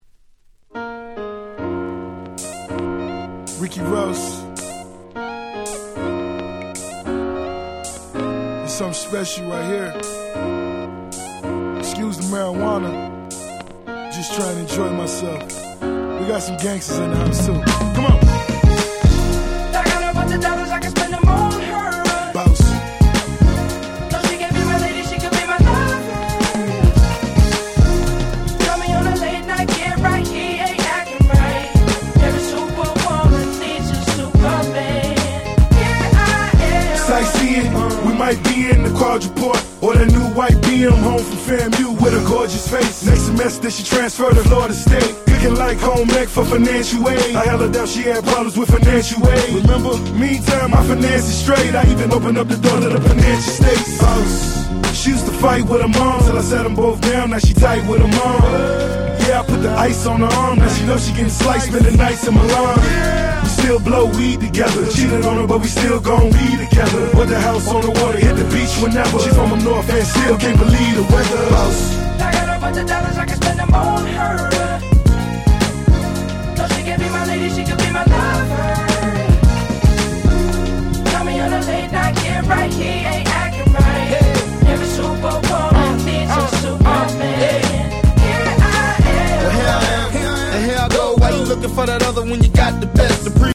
08' Smash Hit Hip Hop !!